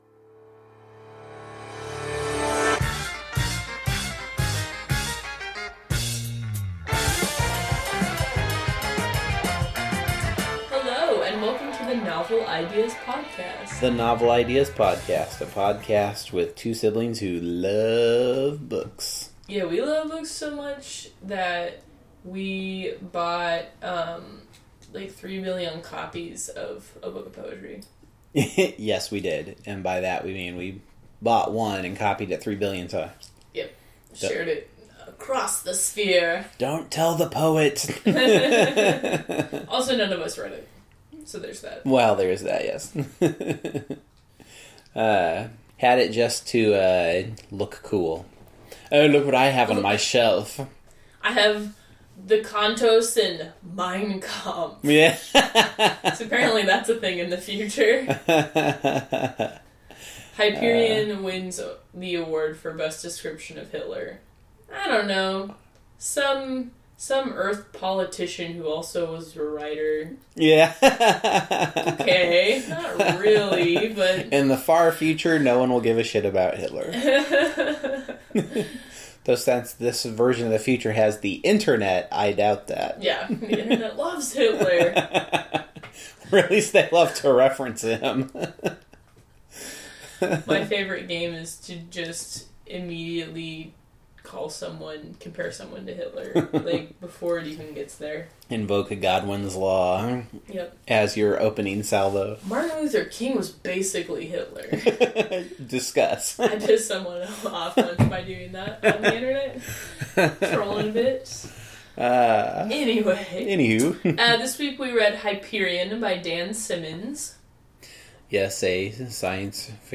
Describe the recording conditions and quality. This episode also features our less than soundproof studio and the various comings and goings of roommates, angry post-surgical cats 50-hyperion-background-noise.mp3